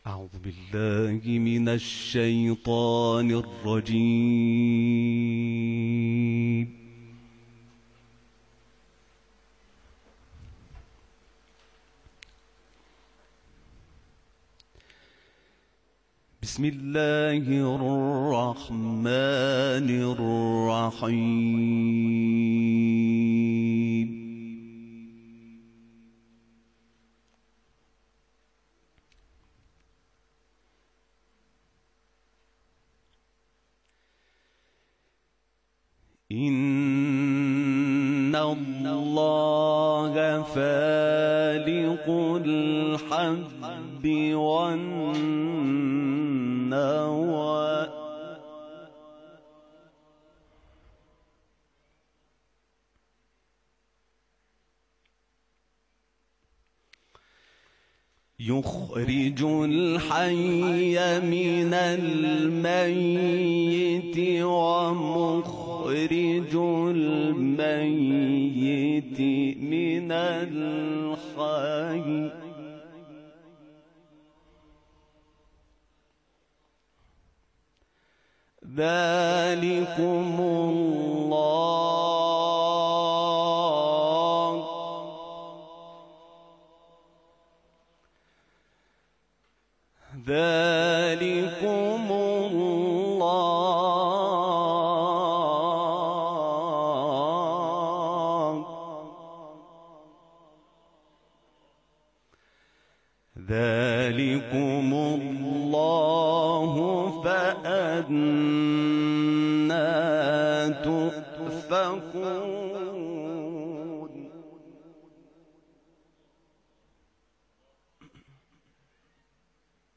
صوت تلاوت آیات ۹۵ تا ۱۰۱ از سوره «انعام» با صدای حمید شاکرنژاد، قاری بین‌المللی قرآن تقدیم مخاطبان ایکنا می‌شود.
تلاوت